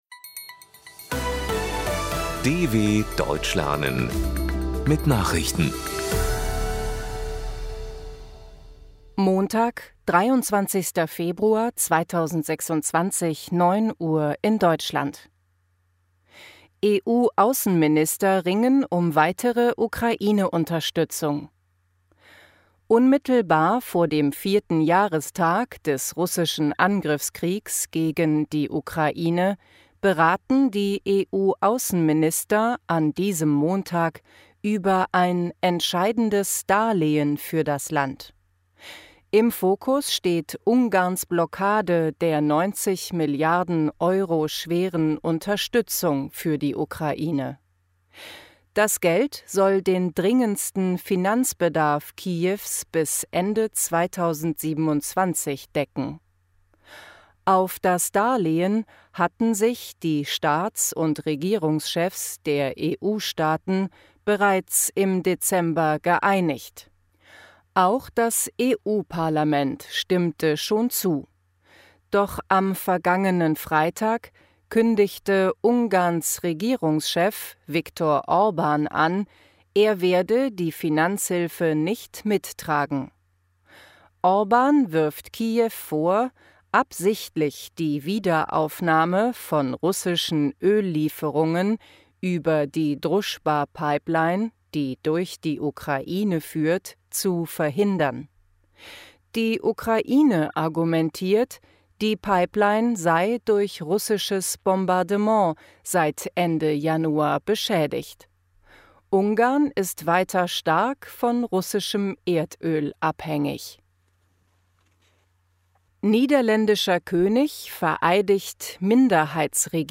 23.02.2026 – Langsam Gesprochene Nachrichten
Trainiere dein Hörverstehen mit den Nachrichten der DW von Montag – als Text und als verständlich gesprochene Audio-Datei.